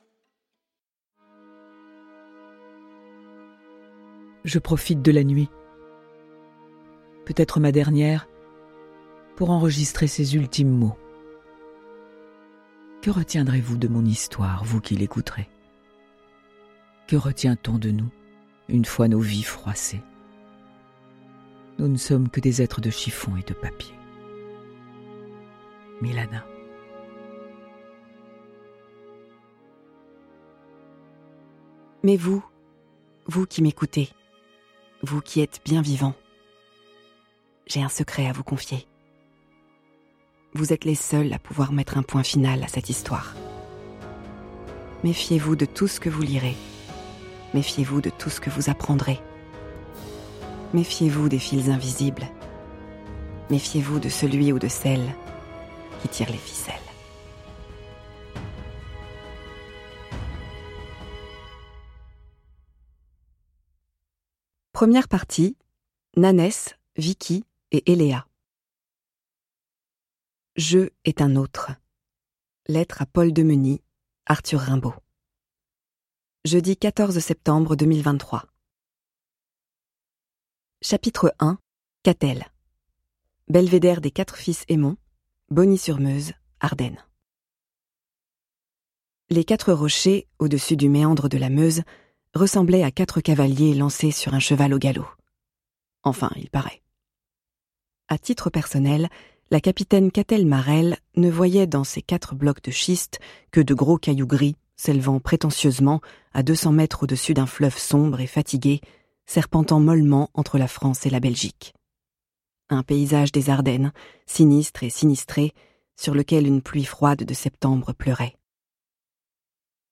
Extrait gratuit